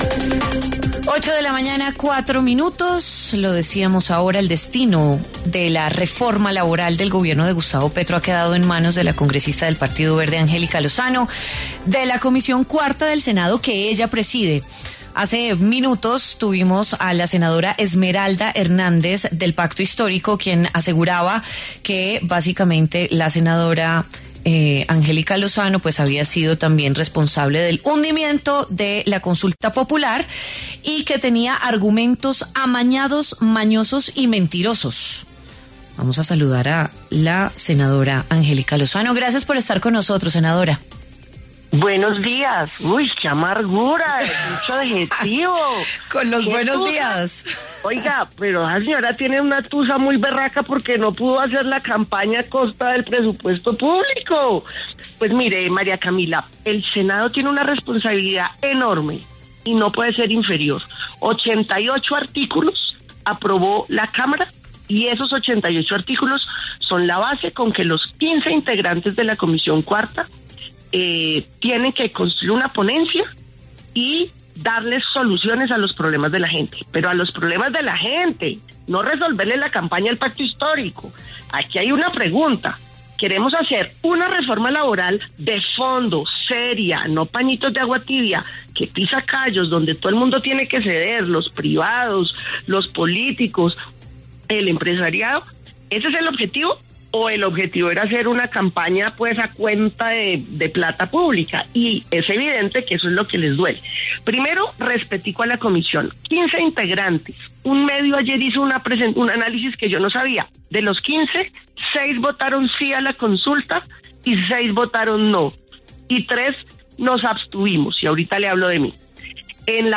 La senadora de la Alianza Verde Angélica Lozano pasó por los micrófonos de W Fin de Semana para conversar sobre el hundimiento de la consulta popular del Gobierno Petro y lo que viene para la reforma laboral.